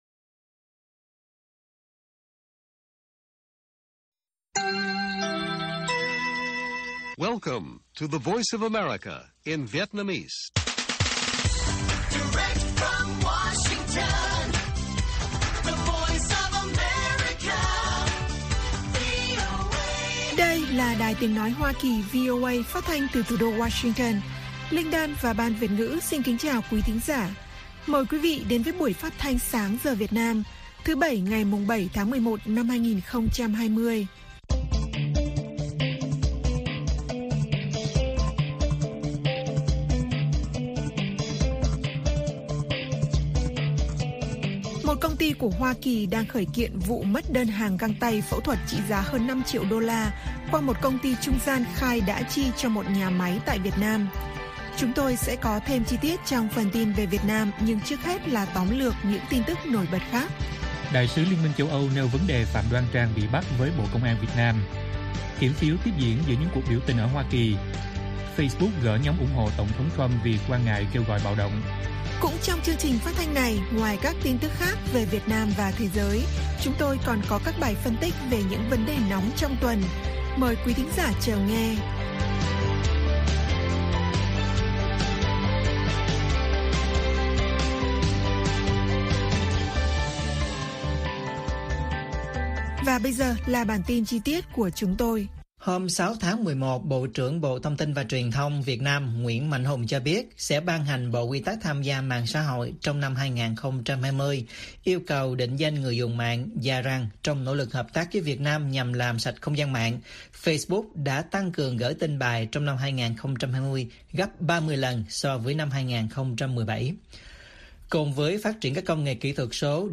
Bản tin VOA ngày 7/11/2020